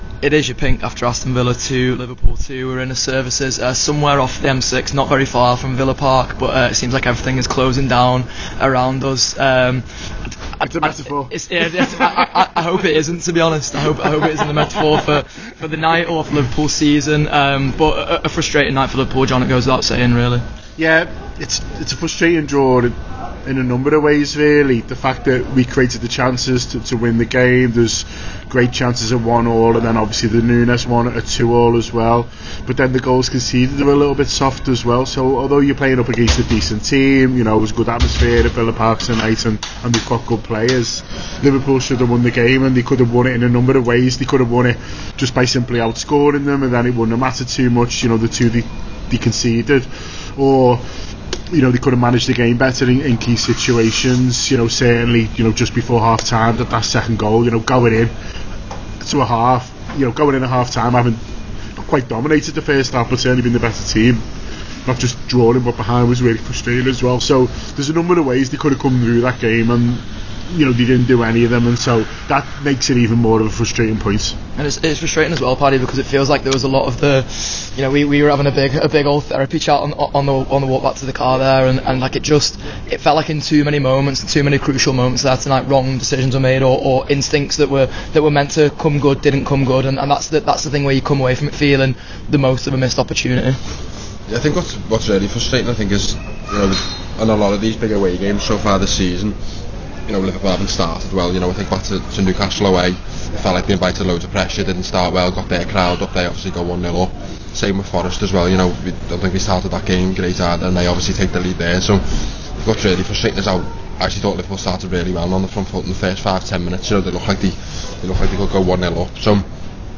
The Anfield Wrap’s post-match reaction podcast after Aston Villa 2 Liverpool 2 at Villa Park.